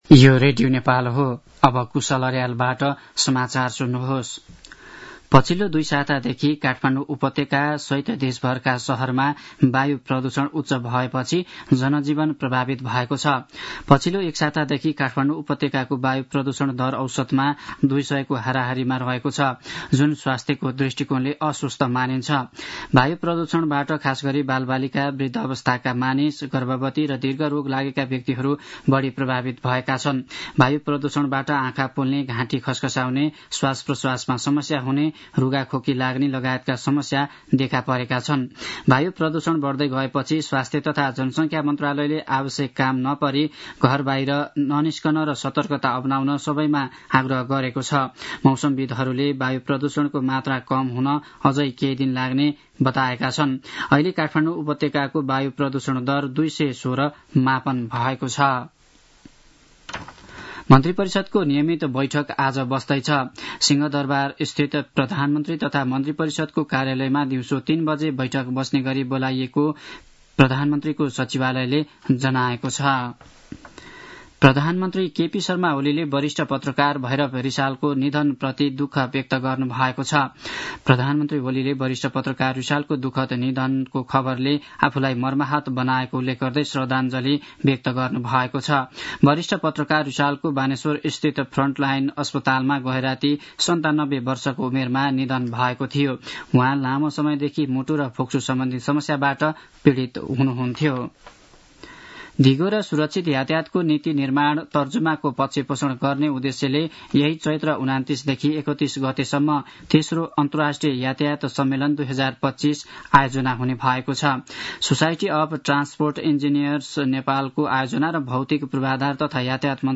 दिउँसो १ बजेको नेपाली समाचार : २५ चैत , २०८१